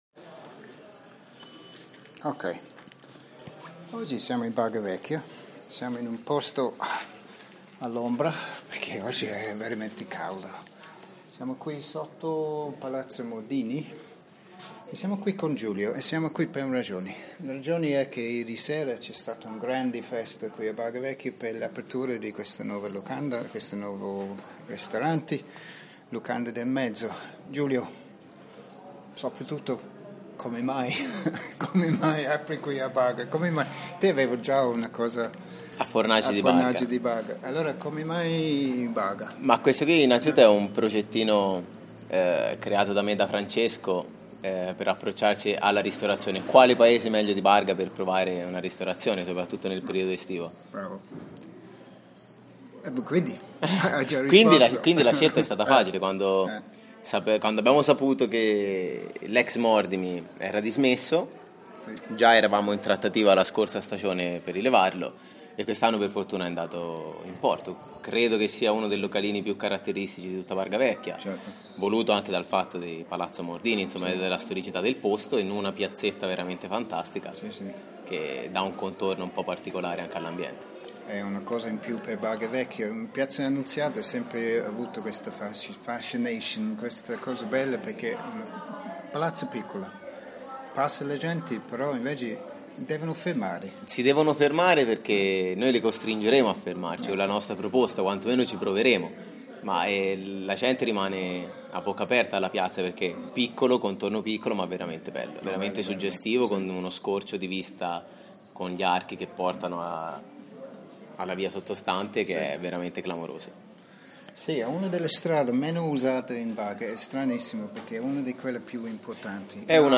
The Aristodemo’s were there to give their musical touch of authentic Italian swing to the proceedings as a huge crowd of interested people surged into the piazza as The Locanda di Mezzo opened its doors for the first time.